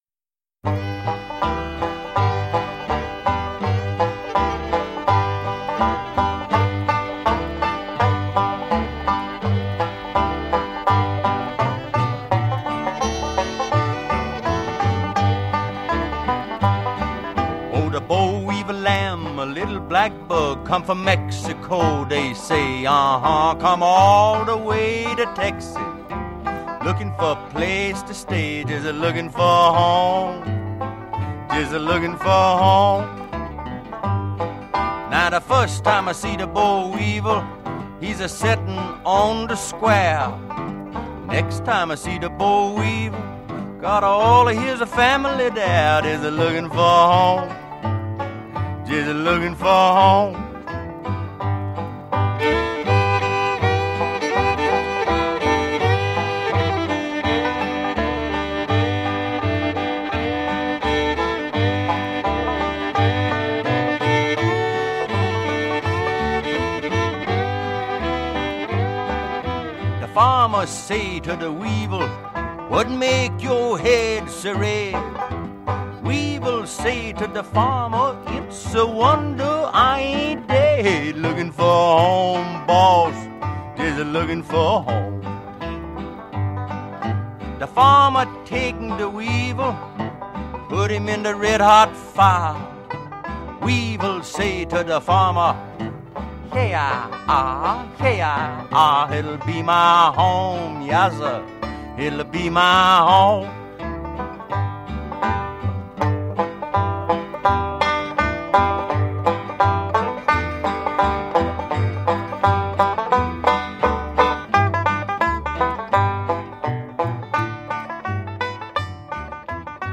Traditional
Listen to Tex Ritter perform "Boll Weevil" (mp3)